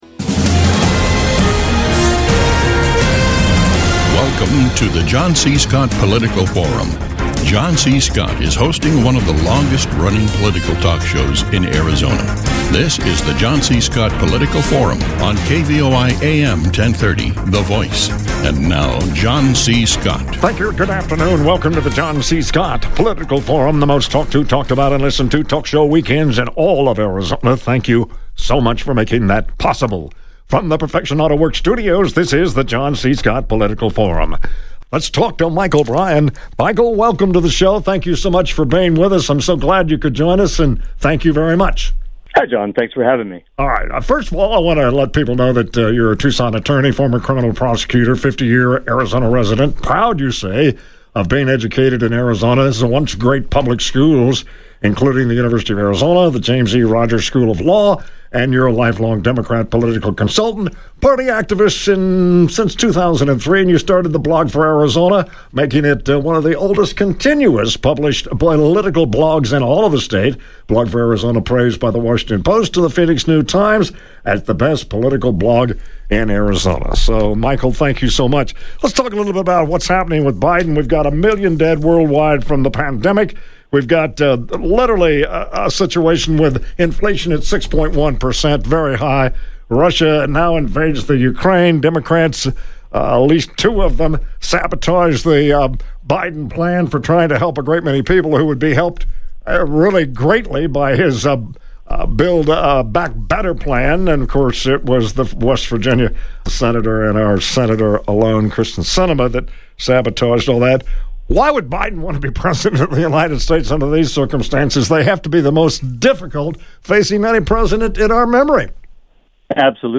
radio talk show